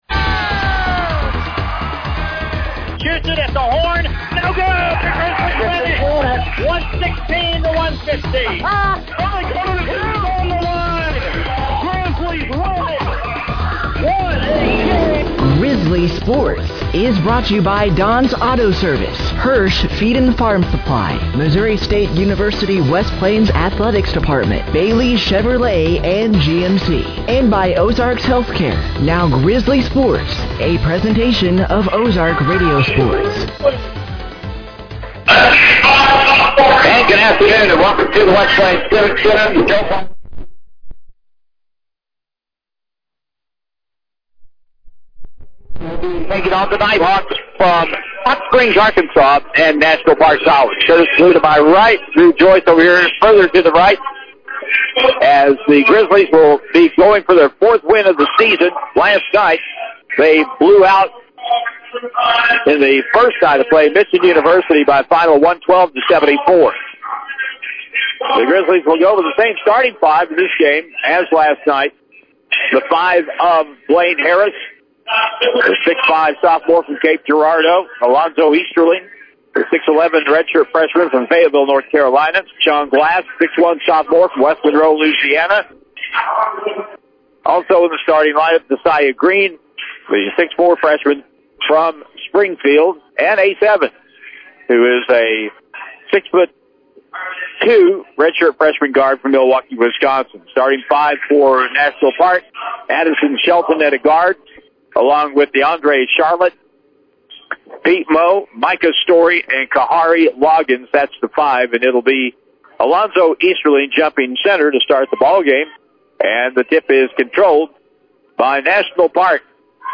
The Missouri State West Plains Grizzlies played their second match of the weekend on their home court versus The National Park Nighthawks from Hot Springs, Arkansas.